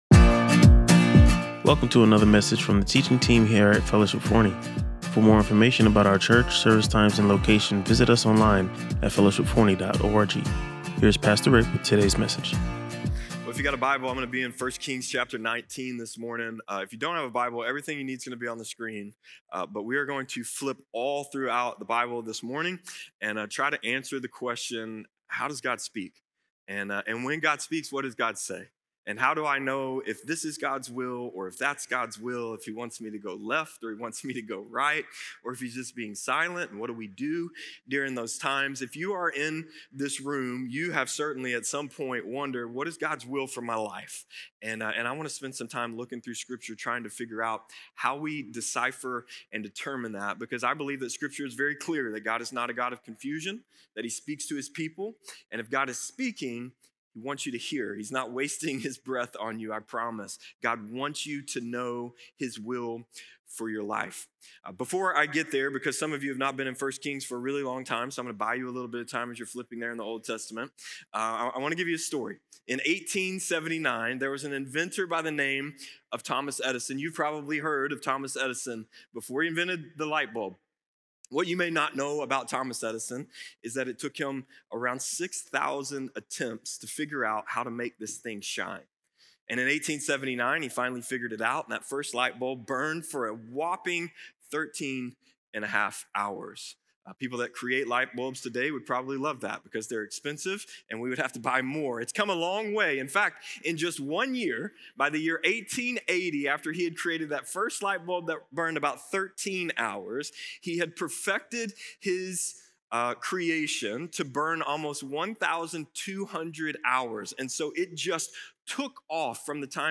He emphasized three key ways to test if we’re hearing from God: measuring against Scripture, seeking the Holy Spirit’s guidance, and consulting godly counsel through Christian community. The message stressed that hearing God’s voice flows from developing a deep relationship with Him through consistent time in prayer and His Word, rather than just seeking specific answers. Listen to or watch the complete sermon and learn more about cultivating a life of hearing God’s voice.